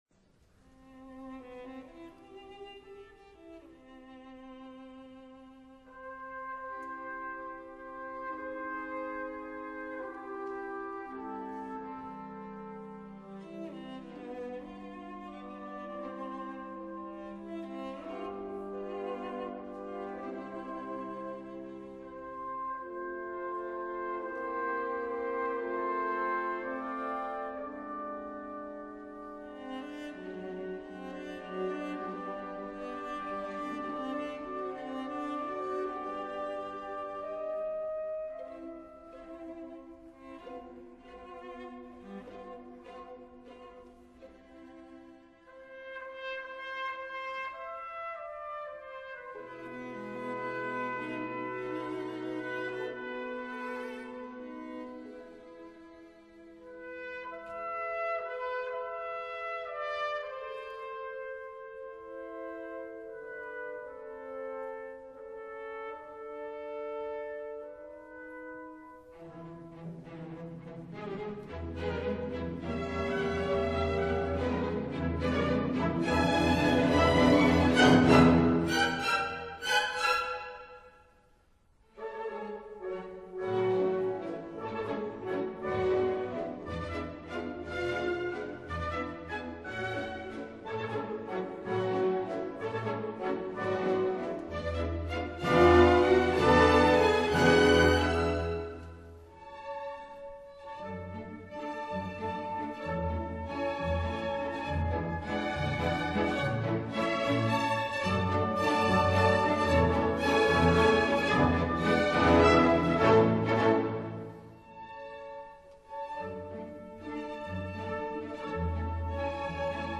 圆舞曲